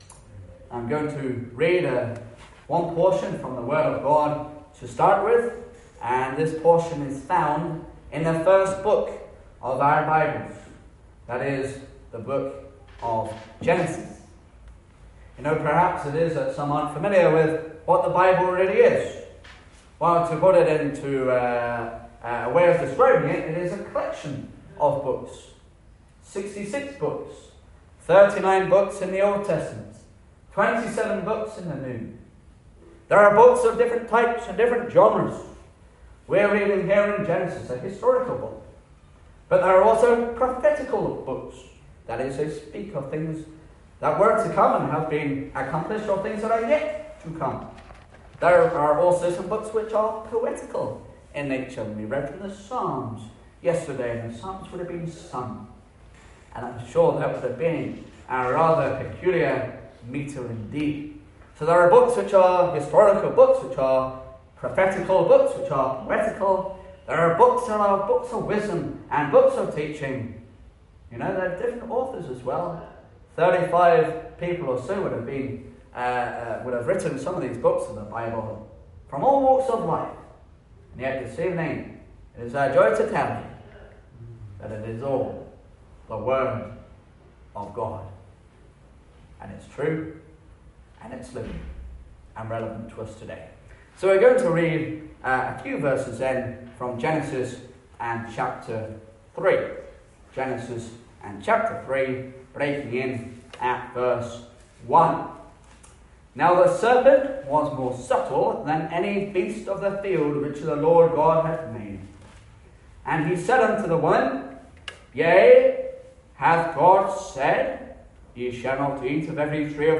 Service Type: Gospel